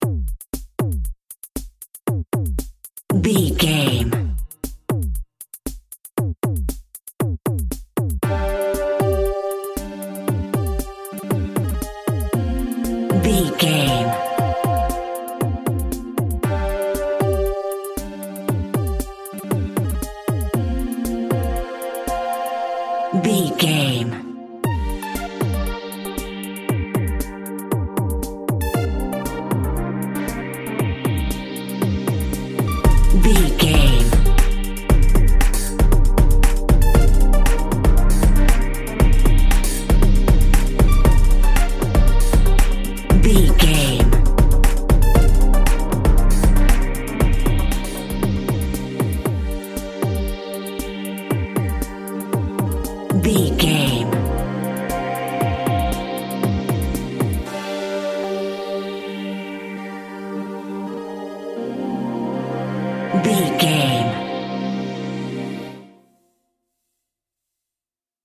Aeolian/Minor
futuristic
smooth
groovy
synthesiser
drum machine
electronic
techno
trance
synth leads
synth bass